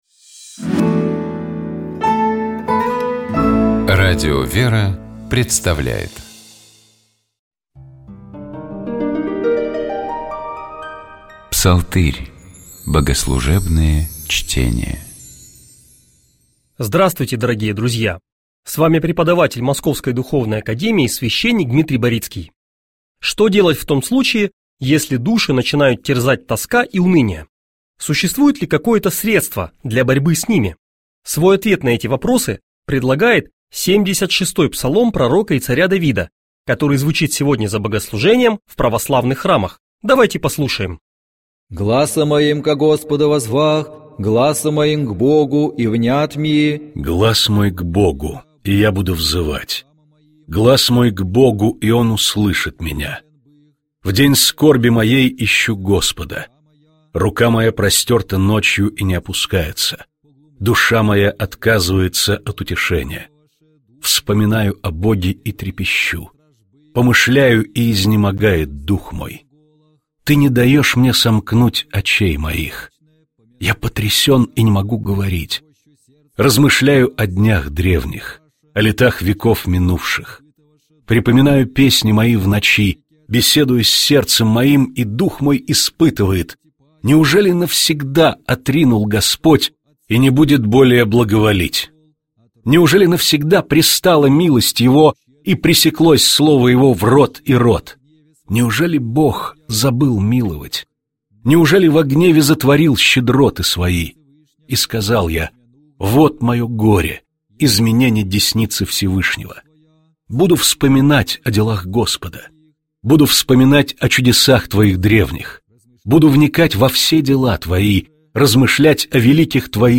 читали и обсуждали фрагменты, посвященные раздражительности, из книги святого праведного Иоанна Кронштадтского «Моя жизнь во Христе».